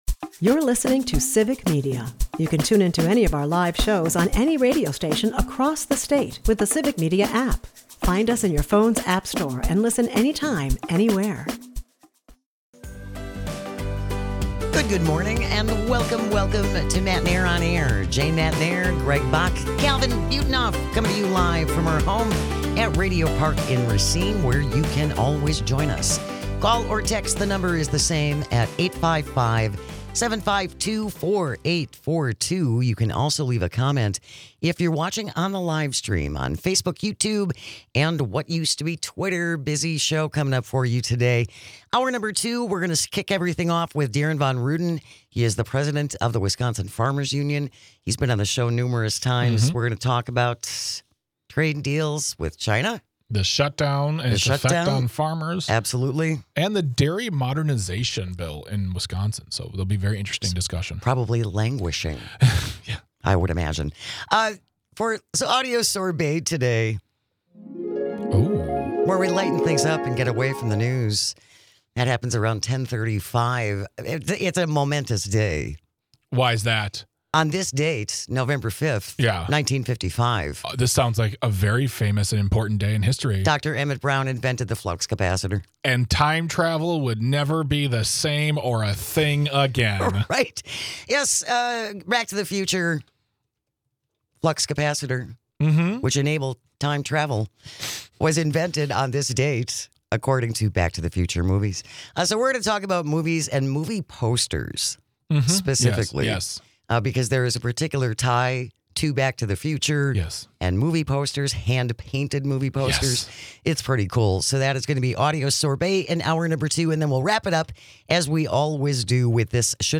We look at the big takeaways and hear from you on what the Left needs to do with this information . As always, thank you for listening, texting and calling, we couldn't do this without you!